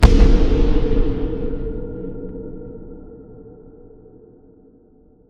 Impact.mp3